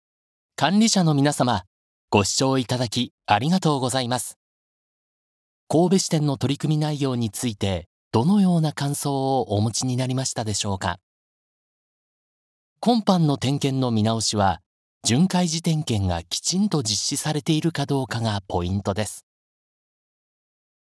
Vielseitige, zuverlässige, warme und beruhigende Stimme, die sich für Geschäfts-, Werbe- und Dokumentarfilme eignet.
Sprechprobe: eLearning (Muttersprache):
Japanese voice over artist. Versatile, reliable, warm and soothing voice suitable for business, commercials and documentaries.